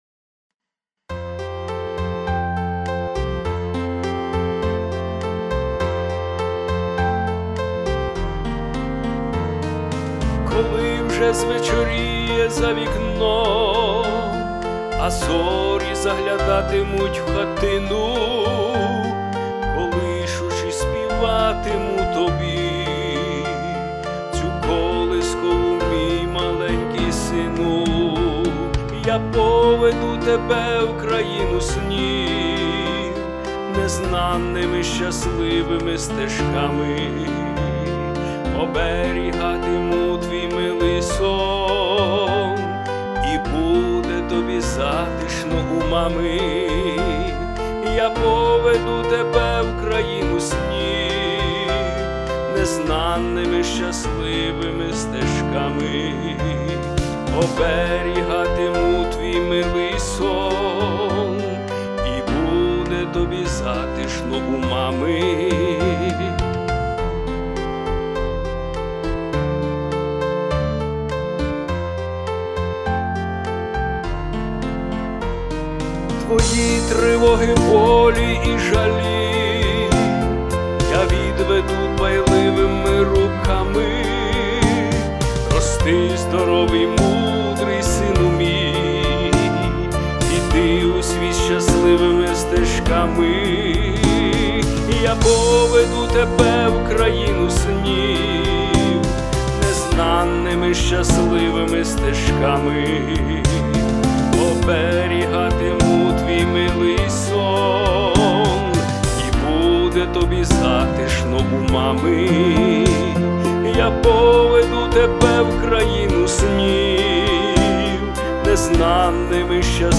Дуже тепла і чуттєва колискова! 16 Молодці!